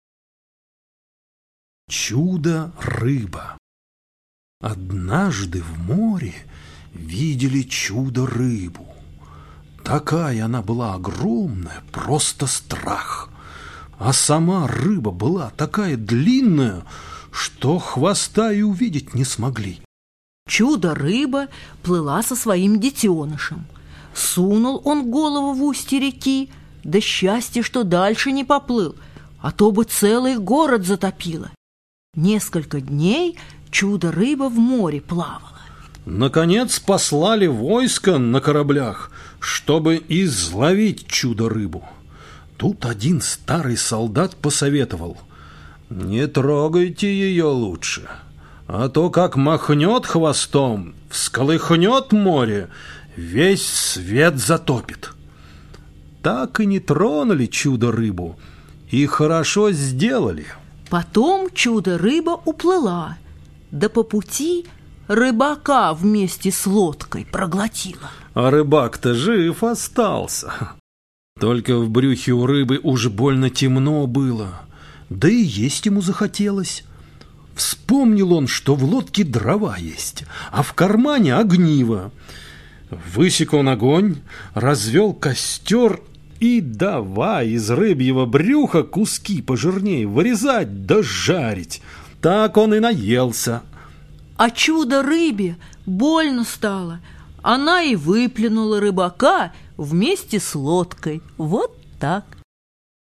Чудо-рыба - латышская аудиосказка - слушать онлайн